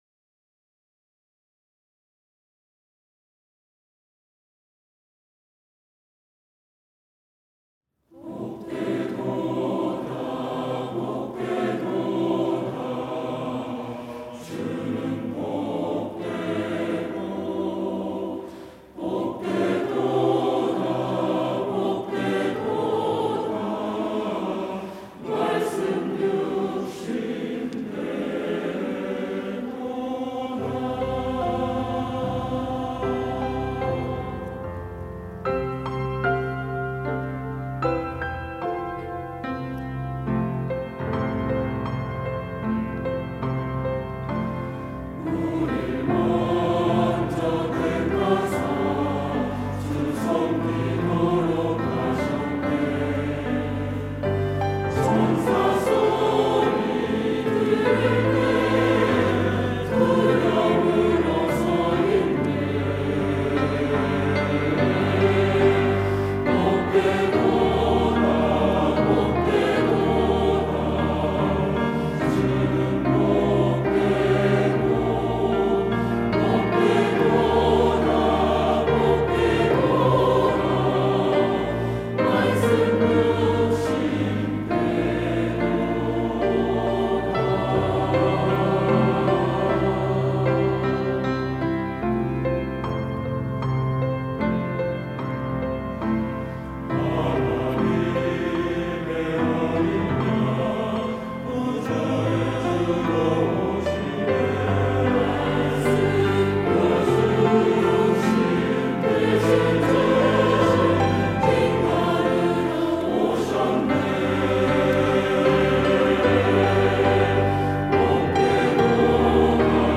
시온(주일1부) - 주는 복되도다
찬양대